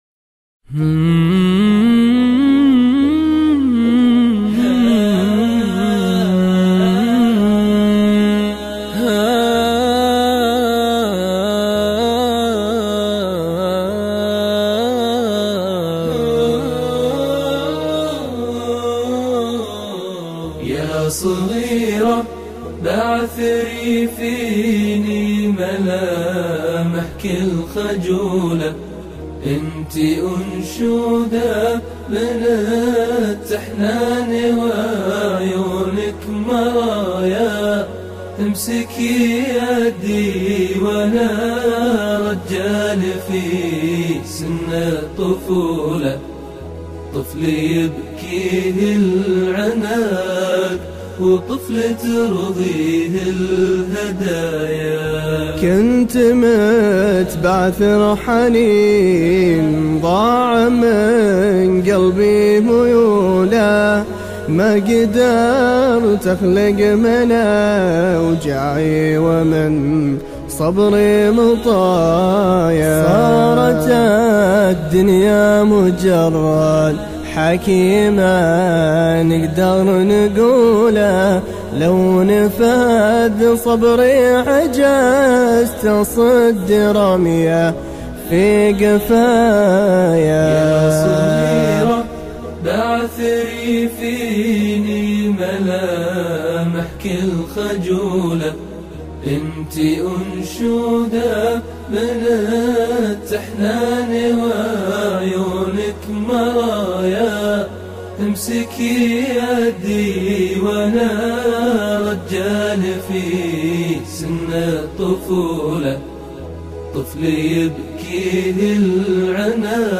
أنشودة